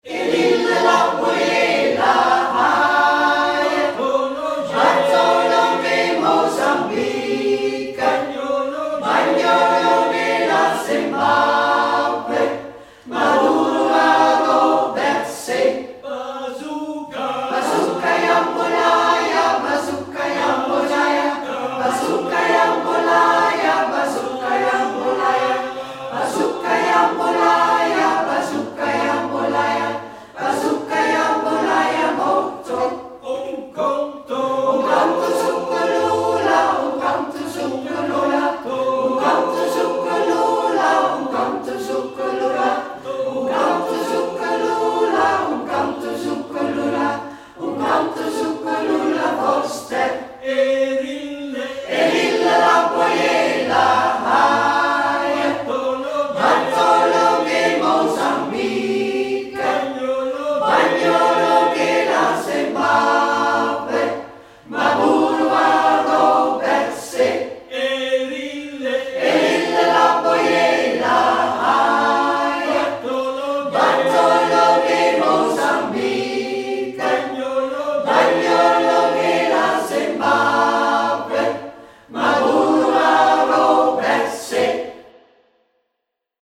ACTIVITEITENDAG 22 JUNI 2025
En het werd nog mooier, want we mochten een lied opnemen. De eerste groep moest eerst wat ‘pionieren’ tot er een goede opname was en de tweede groep hoefde alleen maar met de al opgenomen stemmen mee te zingen.
samenzang van het hele koor: